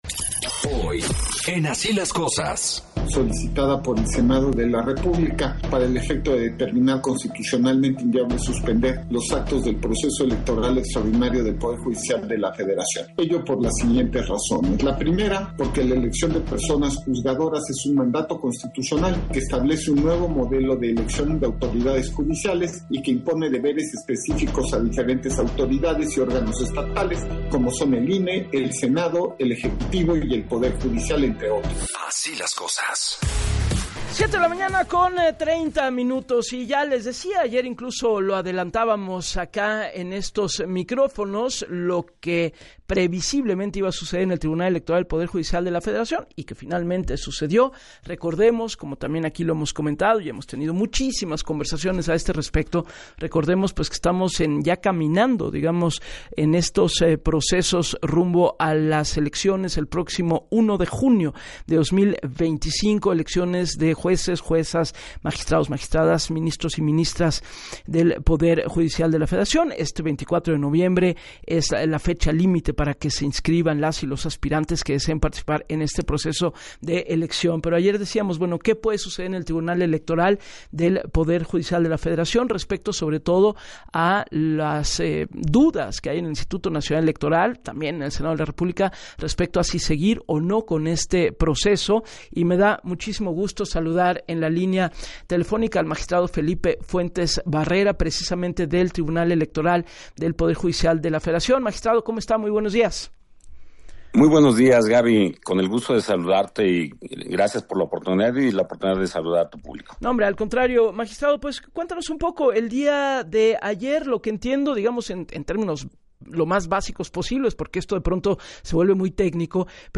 En entrevista para “Así las Cosas” con Gabriela Warkentin, el magistrado puntualizó que el día de ayer se resolvieron las dudas del Senado de la República y del propio INE sobre continuar o no con el proceso electoral, derivadas de la suspensión en materia electoral y los juicios de amparo promovidos por jueces, que señaló.